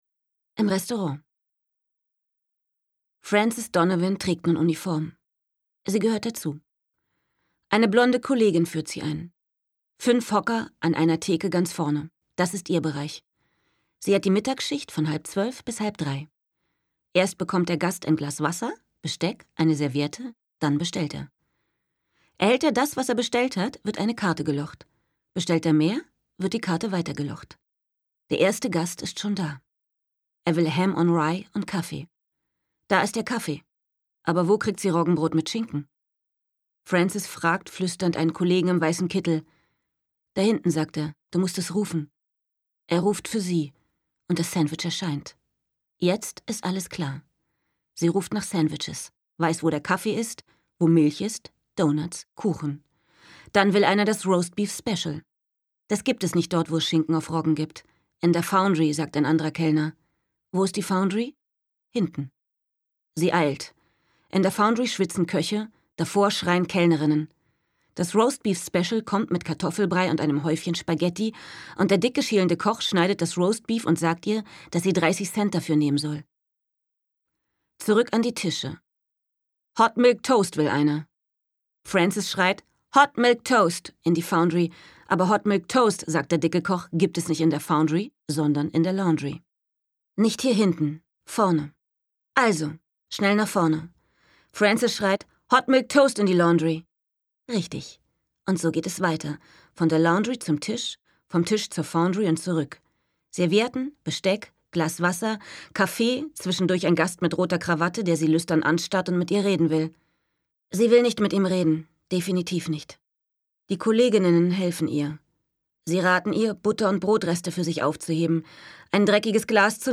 Stimmproben
Sachtext.mp3